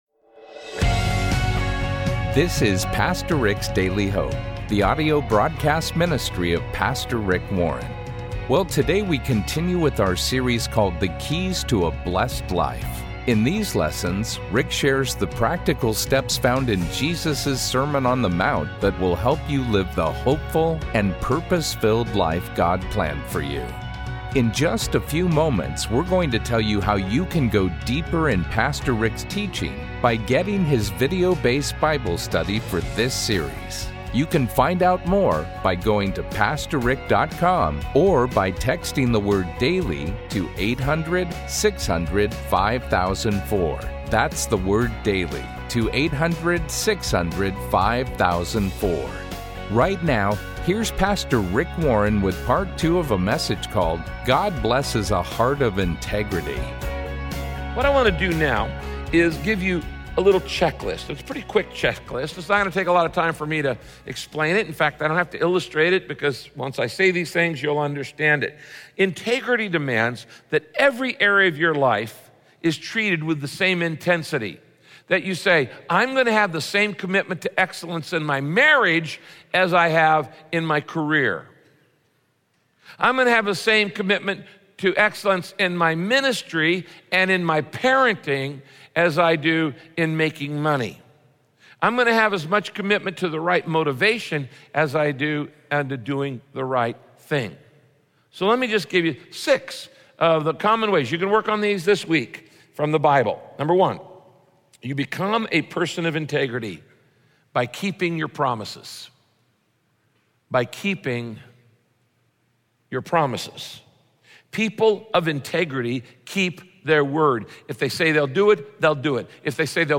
My Sentiment & Notes God Blesses a Heart of Integrity - Part 2 Podcast: Pastor Rick's Daily Hope Published On: Thu May 18 2023 Description: If you are serious about becoming a person of integrity, the first step is to admit that you don’t always act with integrity. In this message, Pastor Rick explains why, if you want to have integrity, you must confess your sins and then make the choice to sin less.